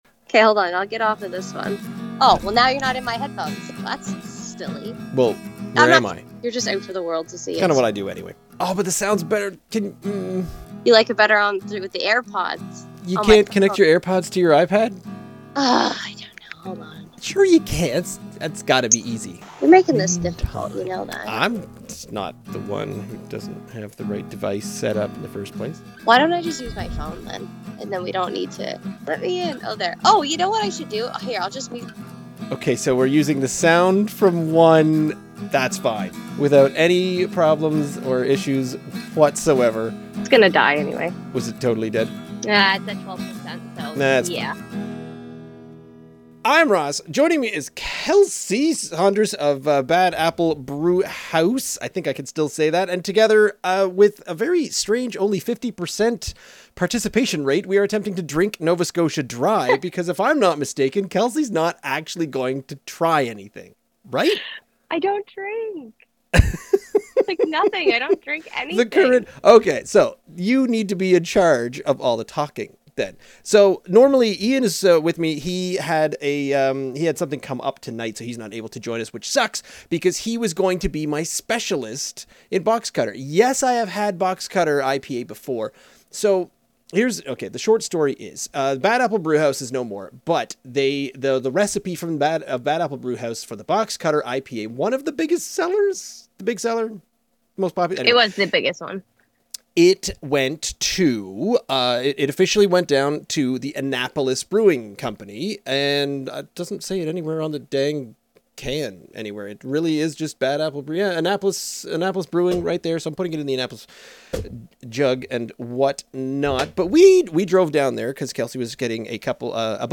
June 13 – A conversation about Bad Apple – Drink Nova Scotia